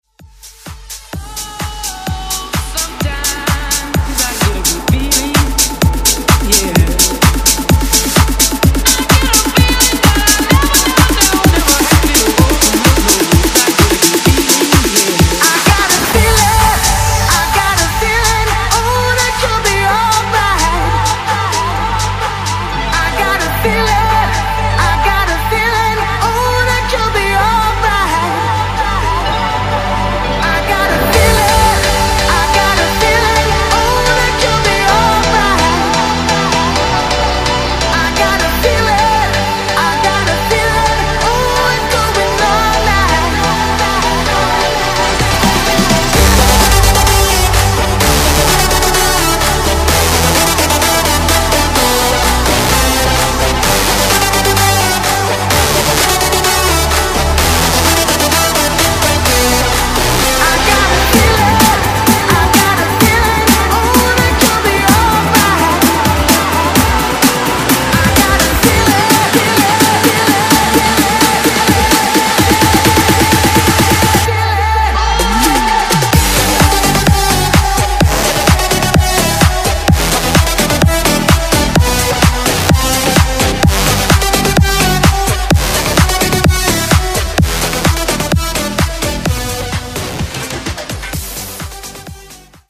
Genres: DANCE , RE-DRUM , TOP40
Clean BPM: 124 Time